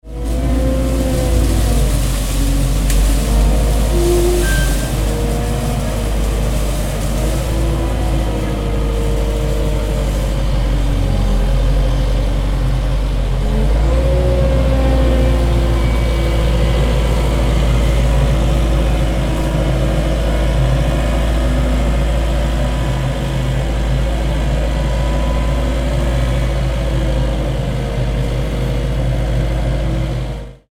Bulldozer Operating Sound Effect
Experience the powerful sound of a real bulldozer in operation, ideal for construction videos, heavy machinery scenes, industrial projects, animations, games, and sound design. This Bulldozer Operating Sound Effect captures engine noise, pushing, digging, and ground movement.
Bulldozer-operating-sound-effect.mp3